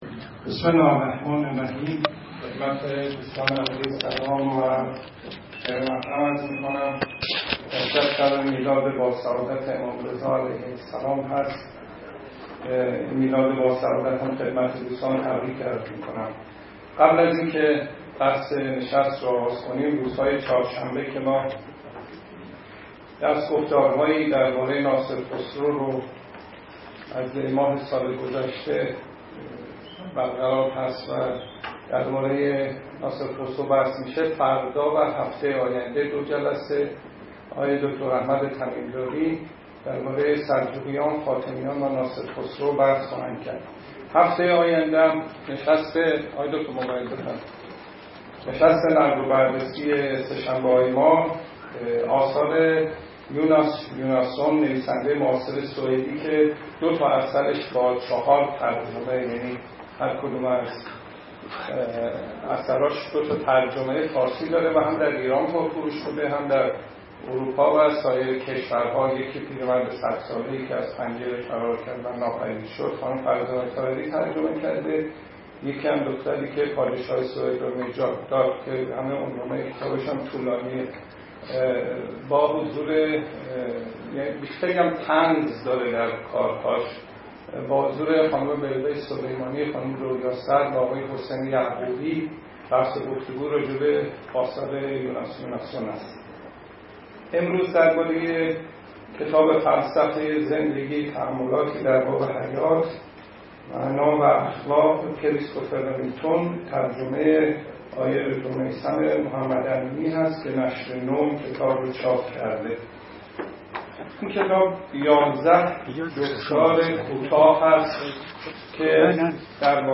این نشست سه‌شنبه سوم شهریور ماه ۹۴ در شهر کتاب برگزار شد.